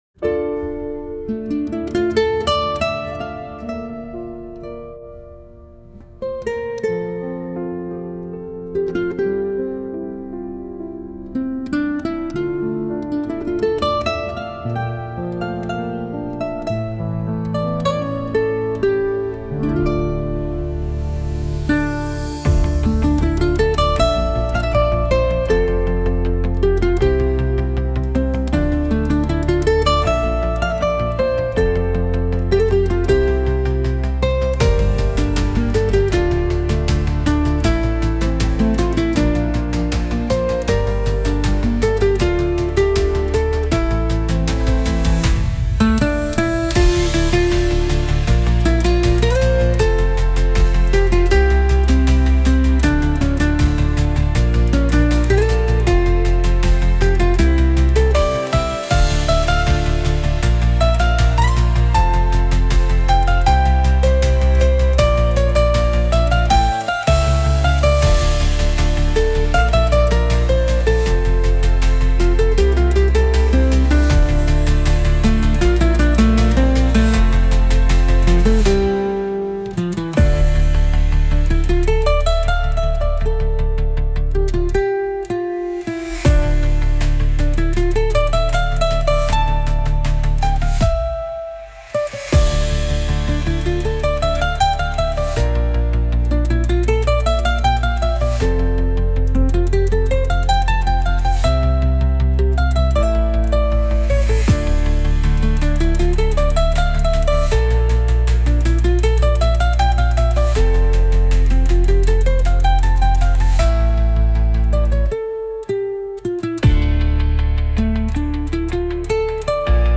Lyrics: (house)